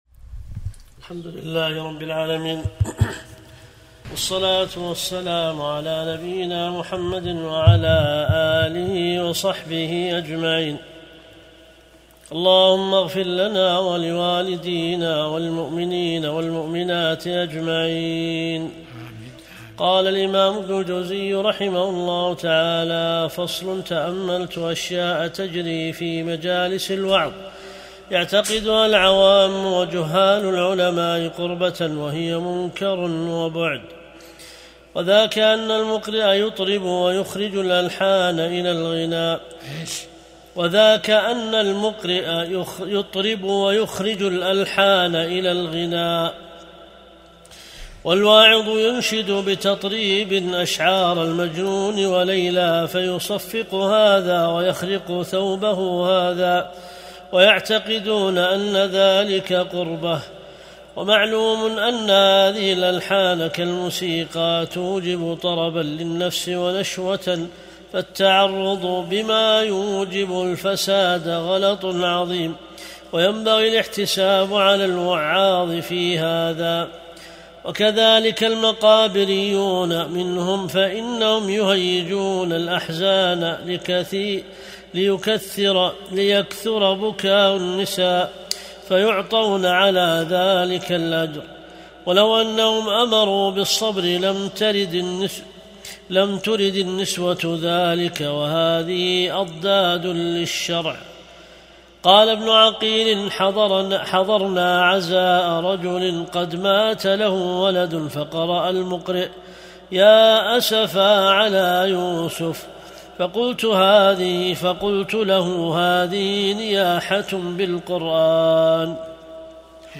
درس الأحد 54